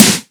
edm-snare-28.wav